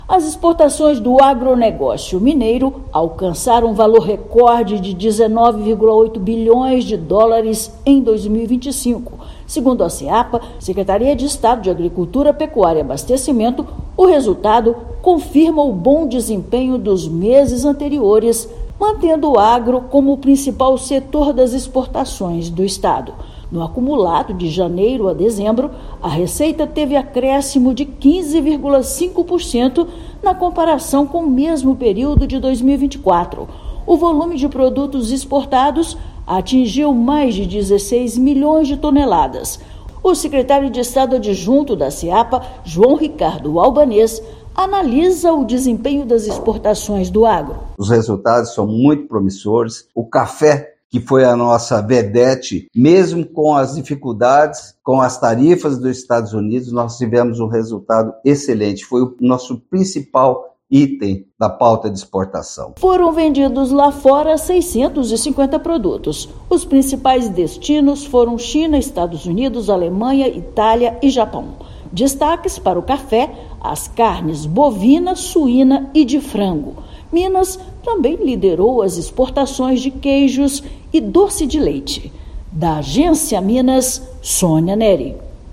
Liderado pelo café, crescimento das exportações de todo o setor foi de 15,5% em relação a 2024. Ouça matéria de rádio.